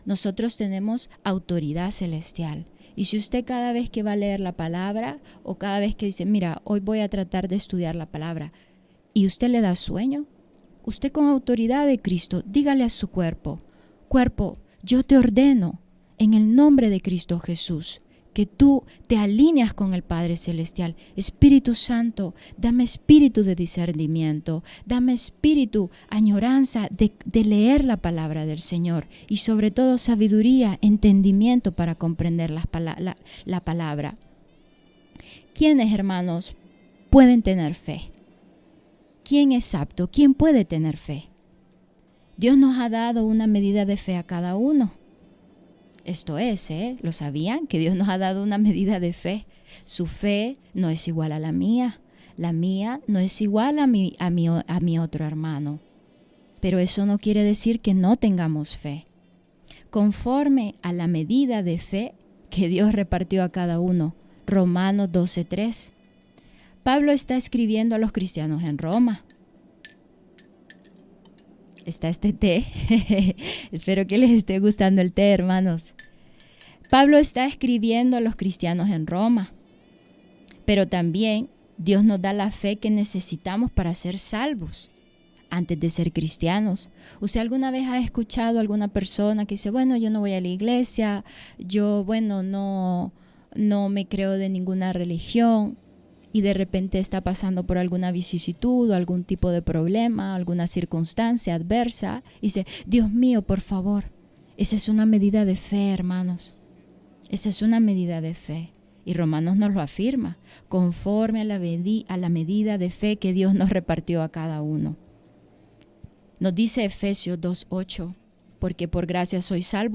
Sermó sobre la fe individual